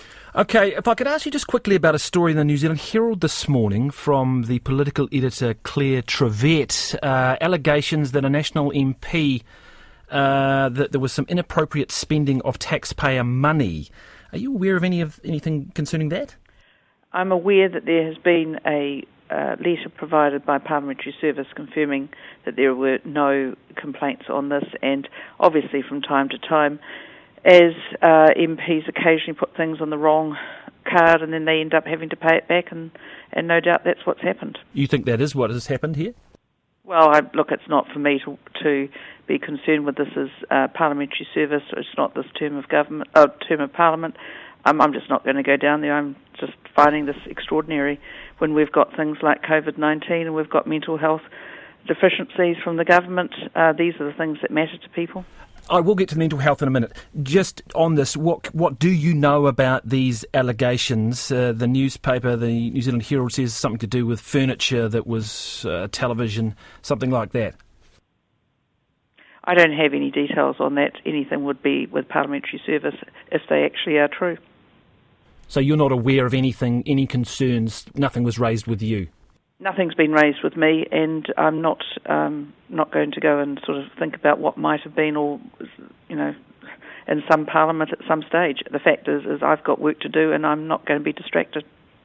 On Radio NZ this morning she claimed to know nothing of the detail.